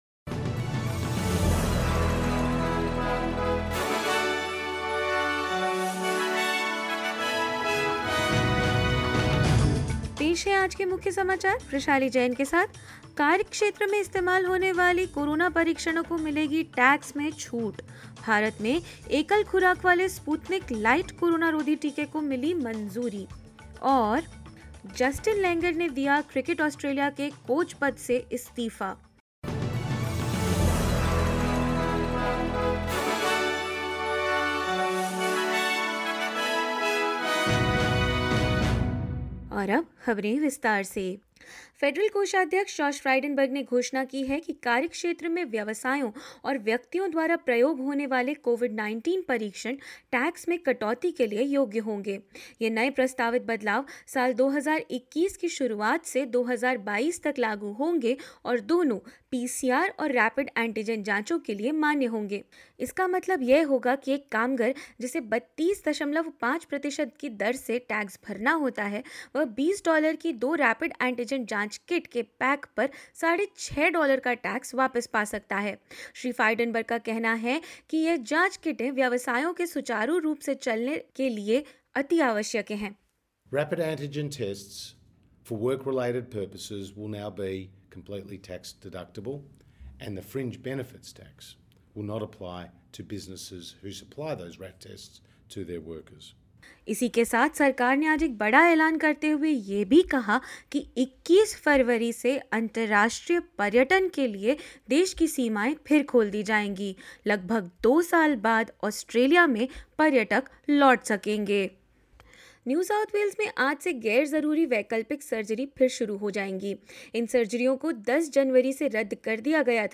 In this latest SBS Hindi bulletin: Australia announces the opening of its international borders from 21 February after almost two years of closure; India approves single dose COVID-19 vaccine, 'Sputnik light' for restricted use; Justin Langer resigns from his position of Australia's cricket coach and more.